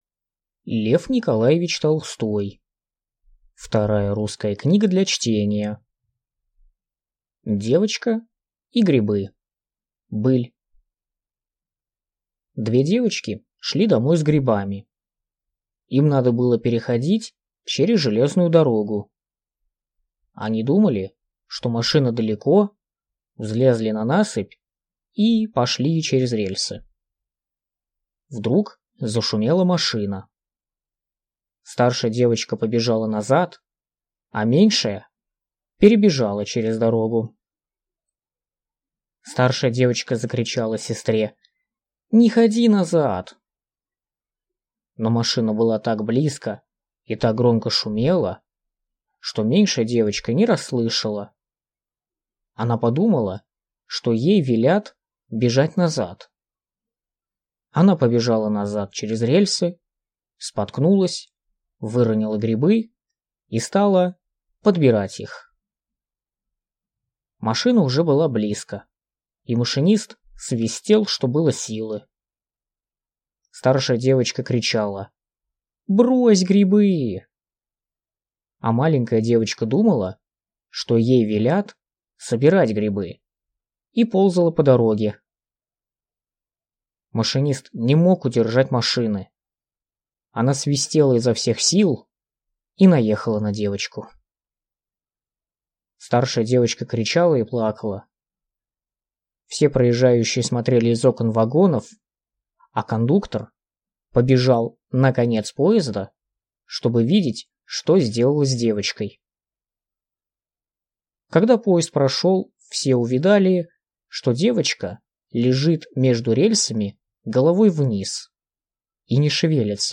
Аудиокнига Вторая русская книга для чтения | Библиотека аудиокниг